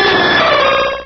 Cri de Ho-Oh dans Pokémon Rubis et Saphir.